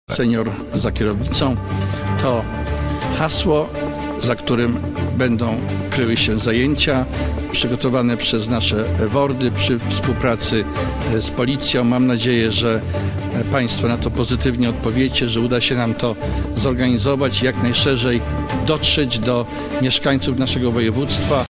– powiedział marszałek Grzegorz Schreiber.